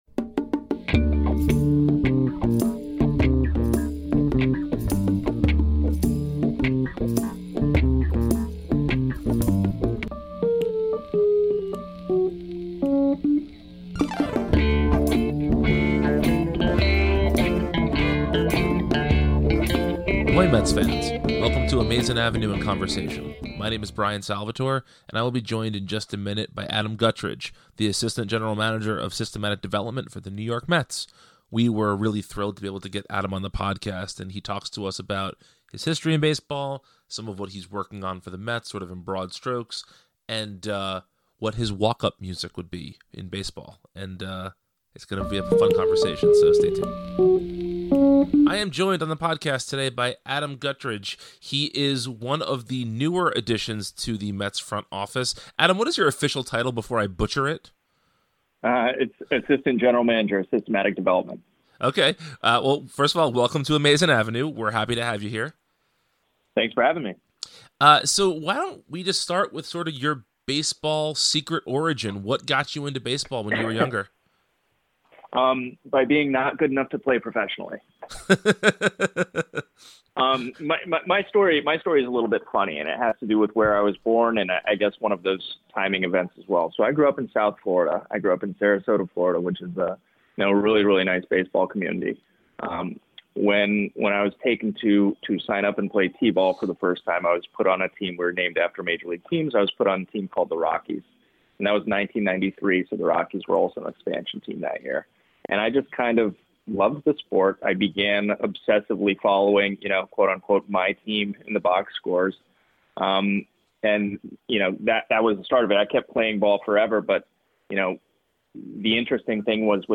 Welcome to Amazin’ Avenue in Conversation, a new podcast from Amazin’ Avenue where we invite interesting people on the show to talk about themselves, the Mets, and more.